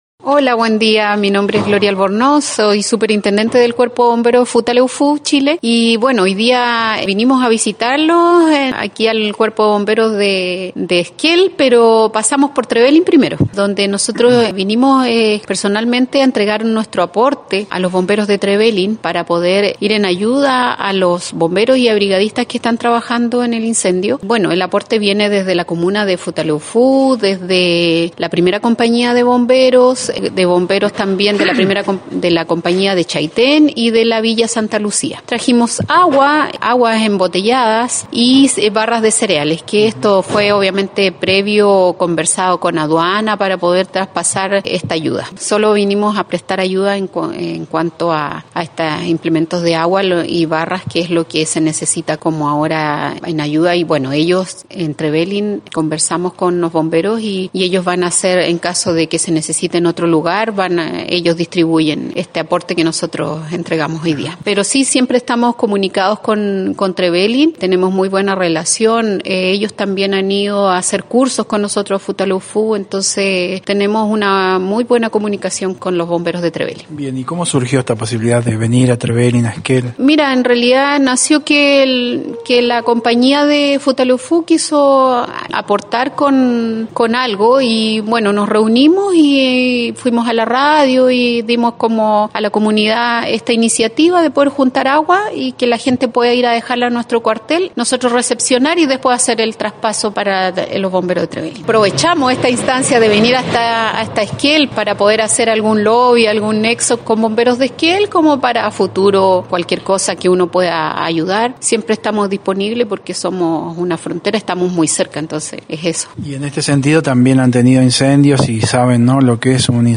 conversó con NOTICIAS DE ESQUEL sobre esta iniciativa que refuerza los lazos de los voluntarios de ambos países.